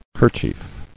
[ker·chief]